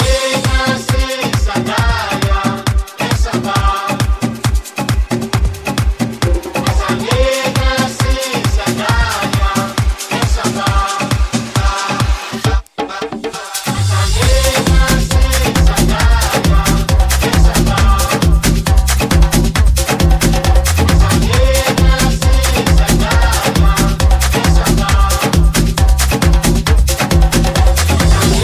Genere: house,salsa,tribal,afro,tech,remix,hit